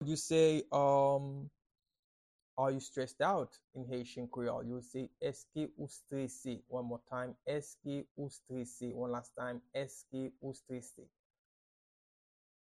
Pronunciation and Transcript:
Are-you-stressed-out-in-Haitian-Creole-–-Eske-ou-strese-pronunciation-by-a-Haitian-Creole-teacher.mp3